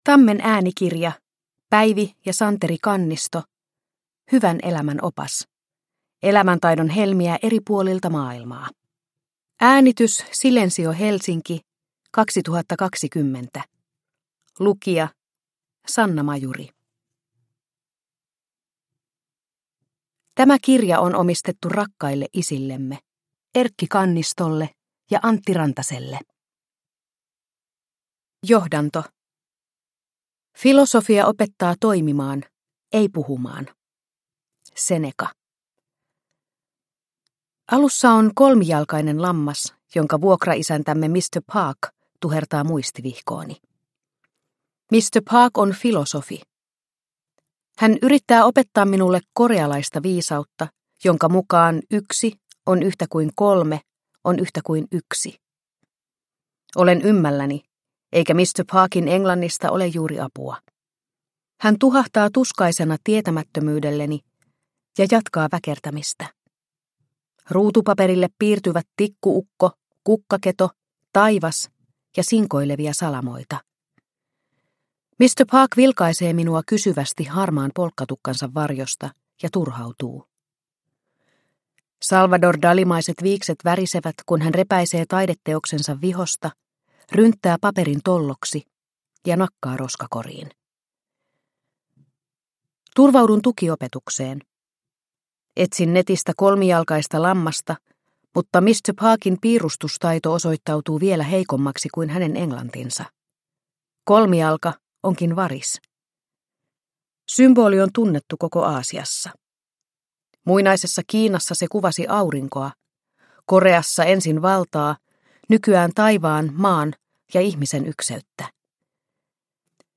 Hyvän elämän opas – Ljudbok – Laddas ner